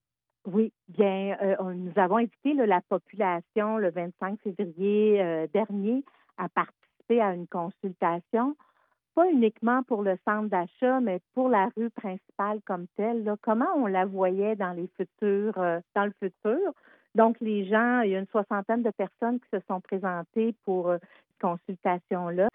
En entrevue dans M105 le matin mardi, la mairesse de Cowansville Sylvie Beauregard a présenté les résultats de la consultation.